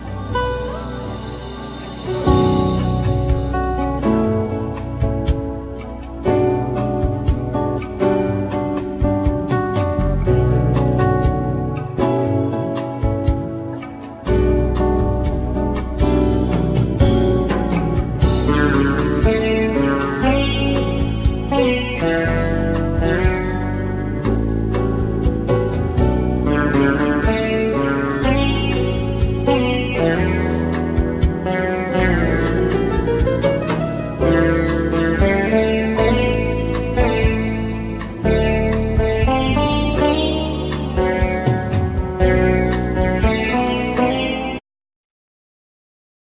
Guitar,Guitar Synth,Programming
Sitar
Tabla
Clarinet